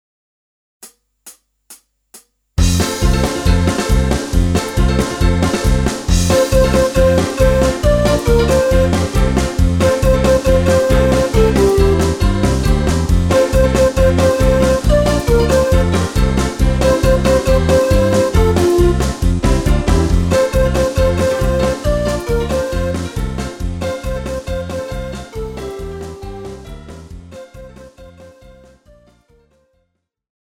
Žánr: Pohádková
BPM: 137
Key: F
MP3 ukázka s ML